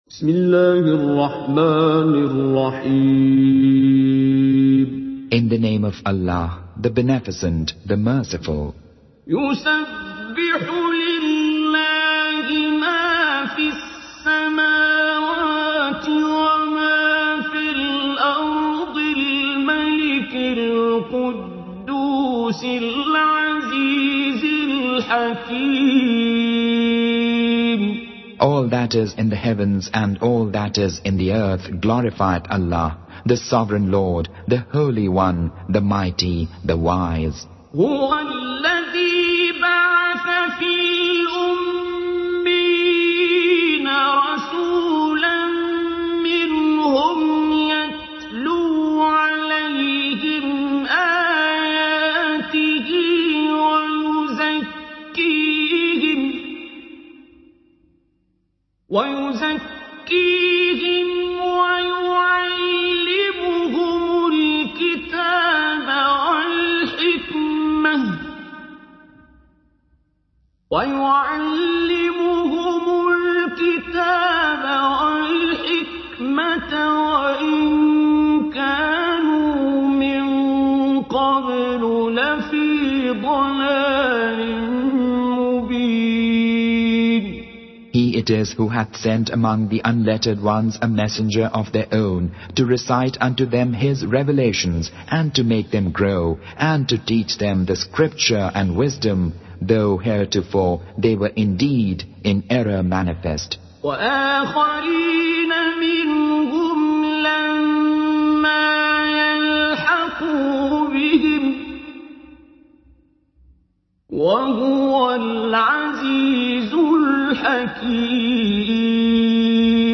Voice: Abd-ur Rahman As-Sudais & Su'ood As-Shuraim, Urdu Tarjuma: Fateh Muhammad Jalandhari